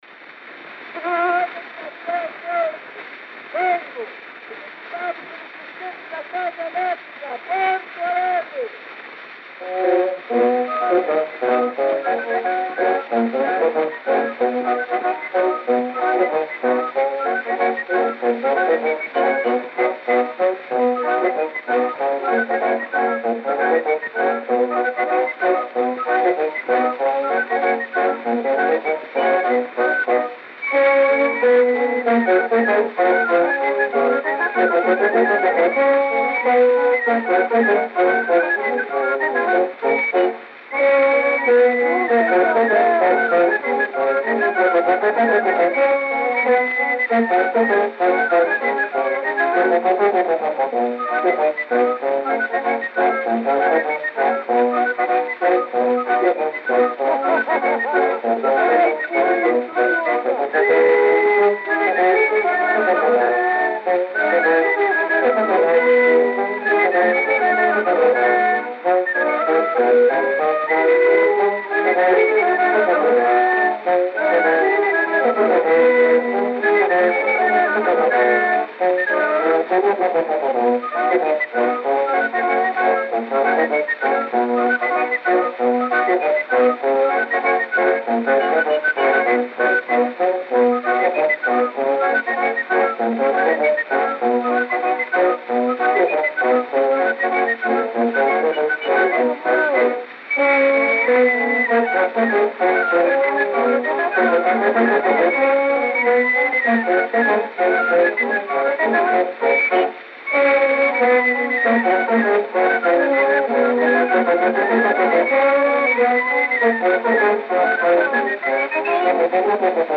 O gênero musical foi descrito como "Tango".